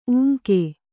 ♪ ūŋke